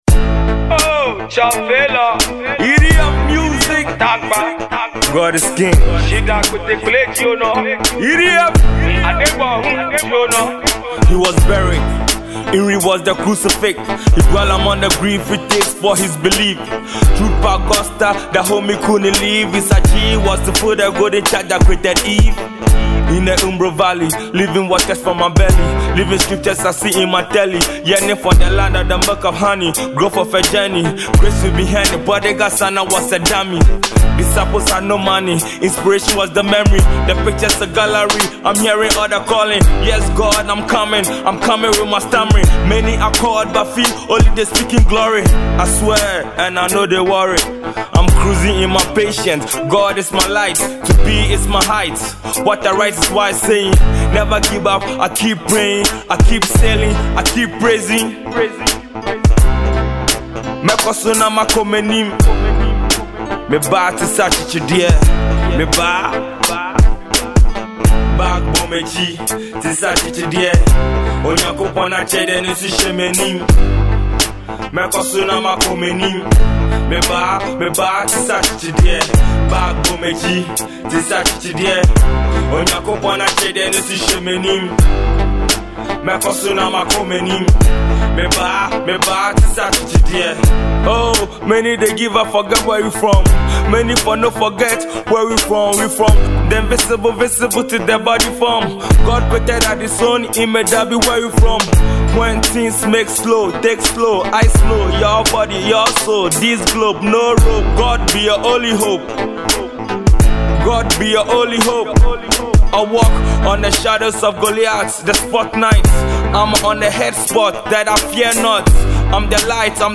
a very sensational urban gospel song